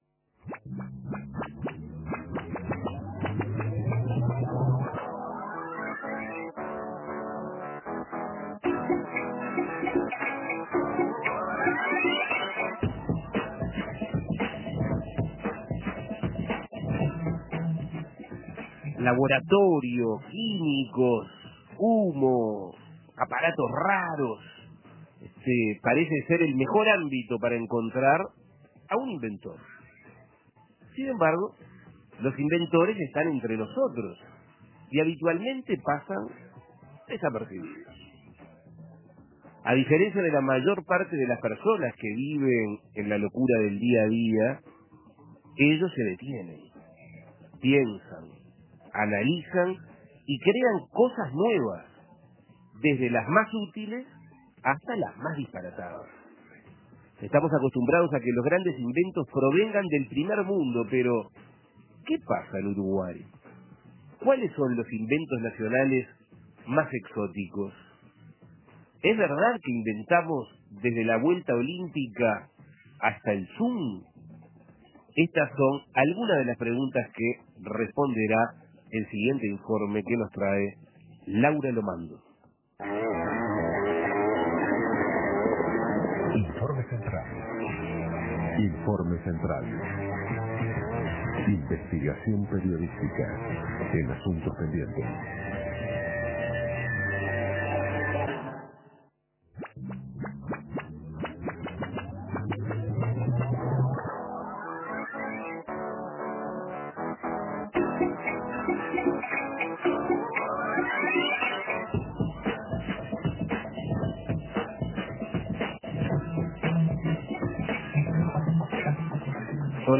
Escuche el informe realizado por Asuntos Pendientes